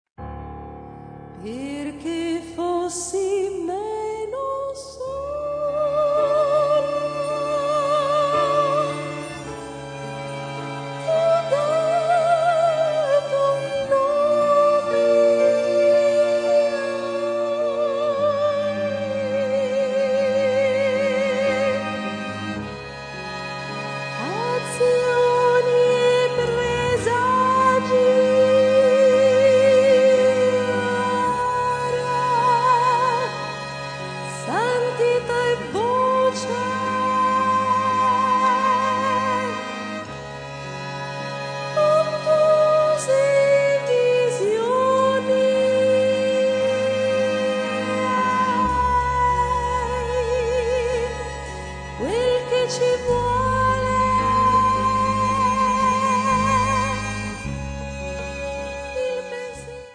flauti
fisarmonica
sax soprano
chitarra
basso elettrico
batteria, percussioni
Quintetto d'Archi: